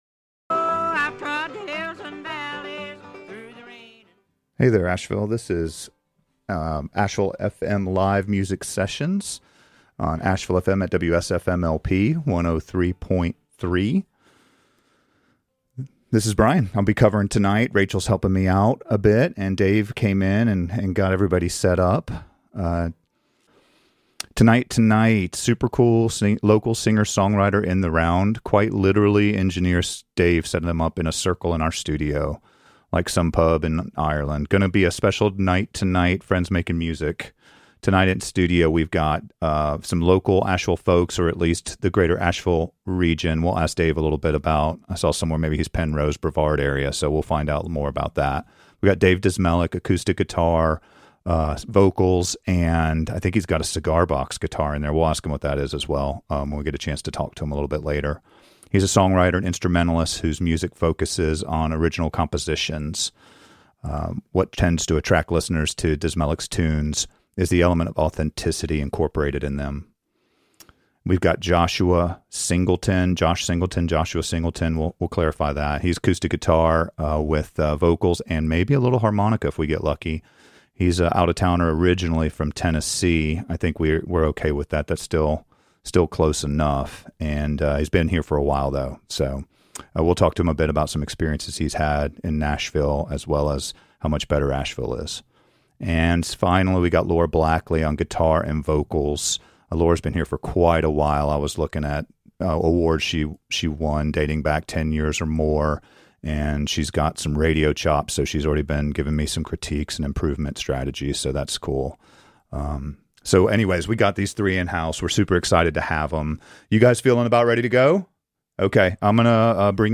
(Singer / Songwriters in the Round)